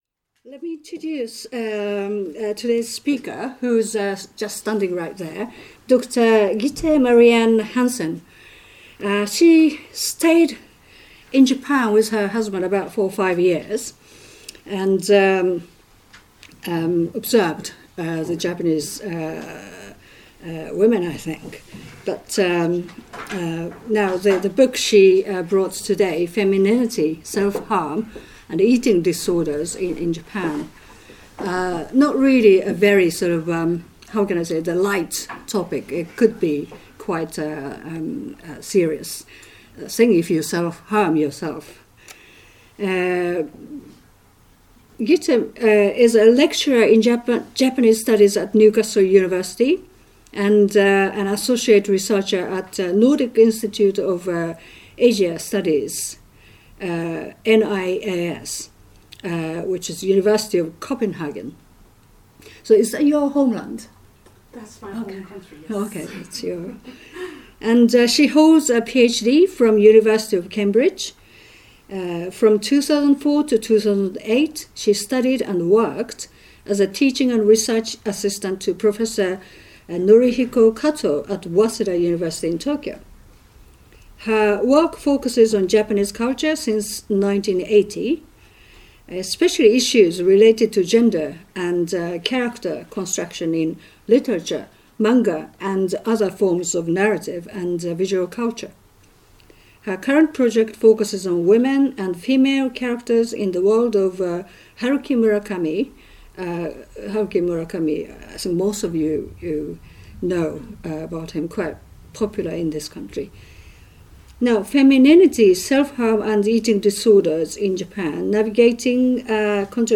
13/14 Cornwall Terrace, Outer Circle (entrance facing Regent's Park), London NW1 4QP
Book launch